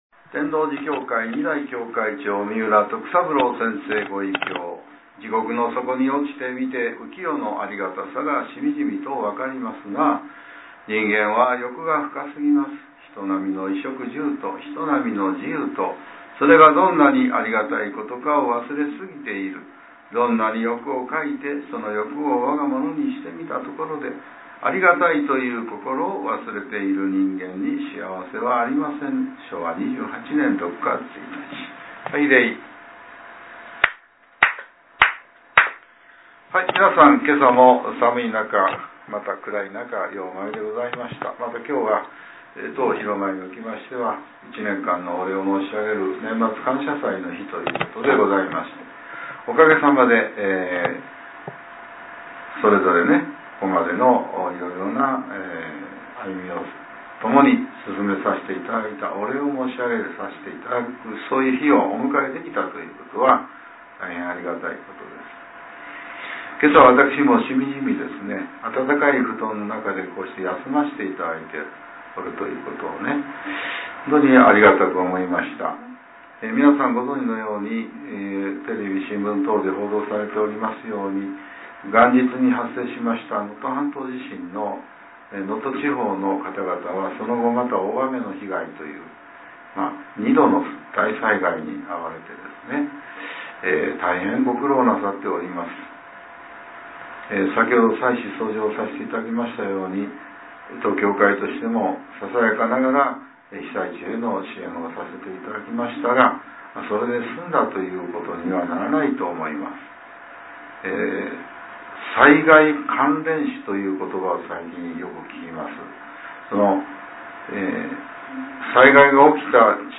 令和６年１２月２９日（朝）のお話が、音声ブログとして更新されています。